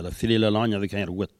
Saint-Jean-de-Monts
Catégorie Locution